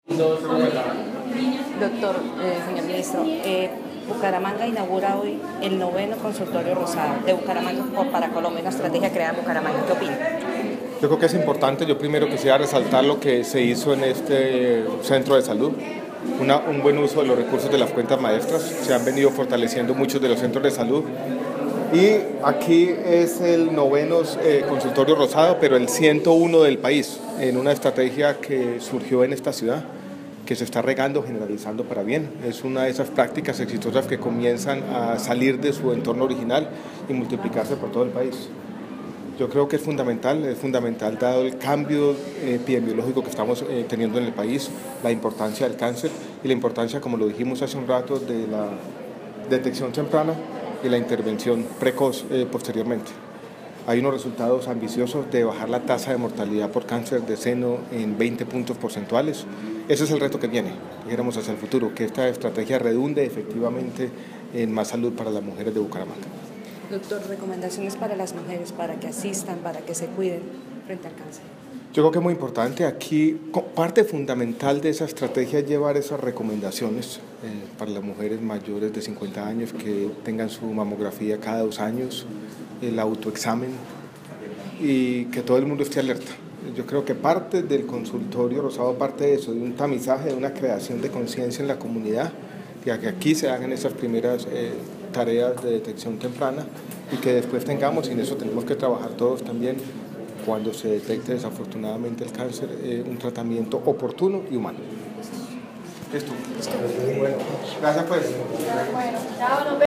Ministro, Alejandro Gaviria en inaguración consultorio rosado
Audio: Declaraciones de MinSalud en lanzamiento de consultorio rosado en Bucaramanga